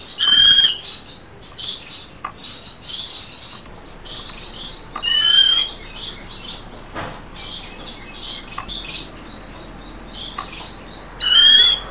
さえずりのコーナー（オオルリ編）
腹減った 165KB おなかが減った時には、こう鳴きます
ruri-harahetta.mp3